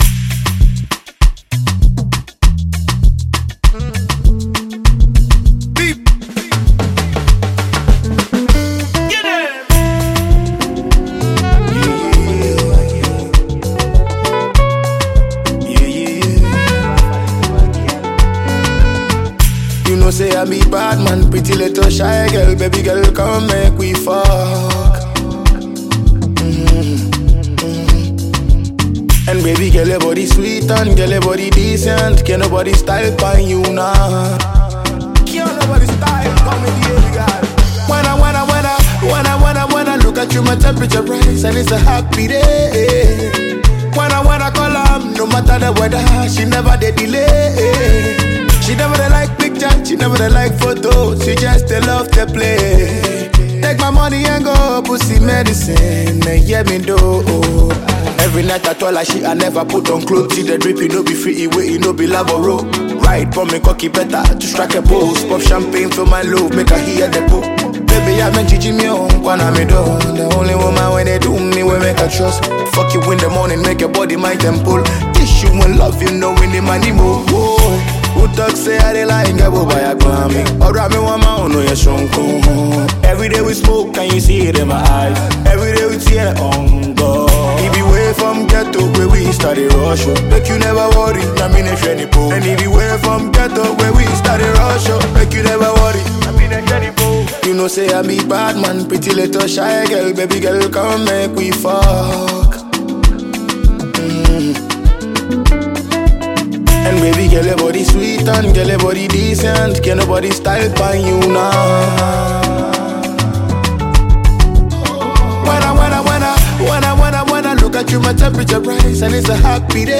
Ghanaian highly-rated afrobeat/afropop artiste